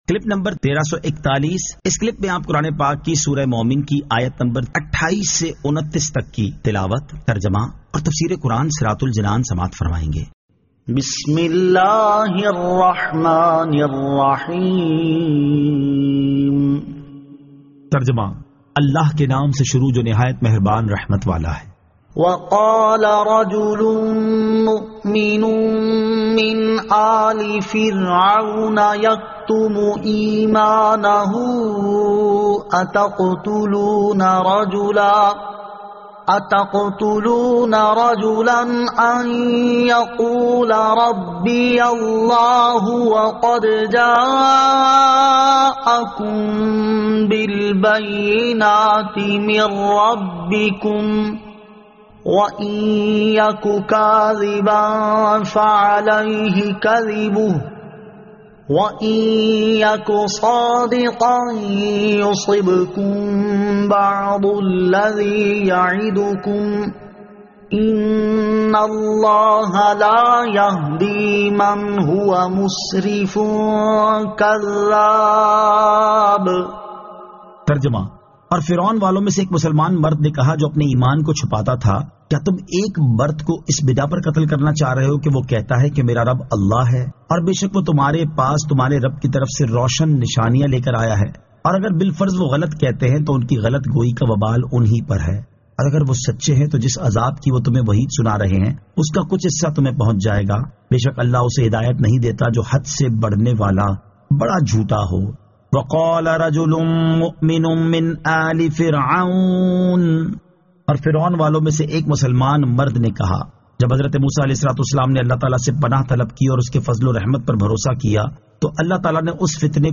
Surah Al-Mu'min 28 To 29 Tilawat , Tarjama , Tafseer